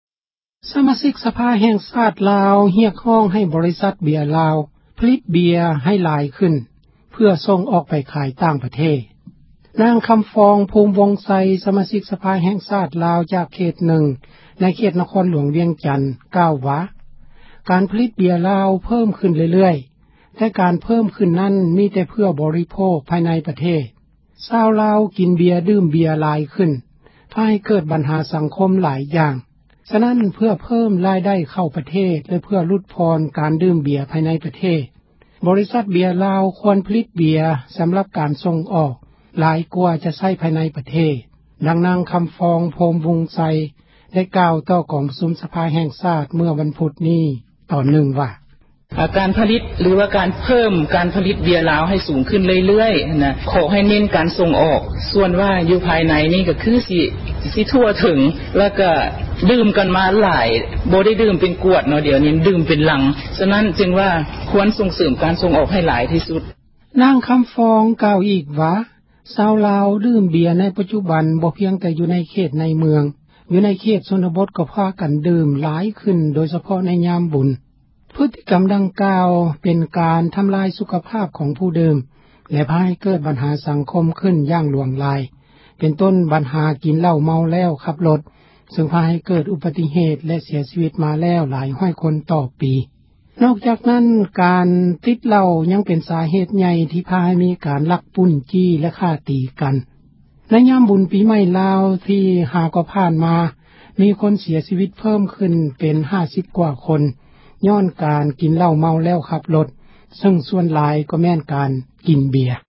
ຊາວລາວ ກິນເບັຍ ດື່ມເບັຍ ຫລາຍຂື້ນ ພາໃຫ້ເກີດ ບັນຫາ ສັງຄົມ ຫລາຍຢ່າງ; ສະນັ້ນ ເພື່ອເພິ້ມ ຣາຍໄດ້ ເຂົ້າ ປະເທດ ແລະ ເພື່ອຫລຸດຜ່ອນ ການດື່ມເບັຍ ພາຍໃນ ປະເທດ ບໍຣິສັດ ເບັຍລາວ ຄວນຜລິດເບັຍ ສຳຣັບ ການສົ່ງອອກ ຫລາຍກວ່າ ຈະໃຊ້ພາຍ ໃນປະເທດ ດັ່ງ ນາງ ຄຳຟອງ ພູມວົງໄຊ ໄດ້ກ່າວຕໍ່ ກອງປະຊຸມ ສະພາ ແຫ່ງຊາດ ເມື່ອວັນພຸດ ນີ້ວ່າ: